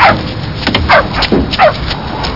Amiga 8-bit Sampled Voice
moo.mp3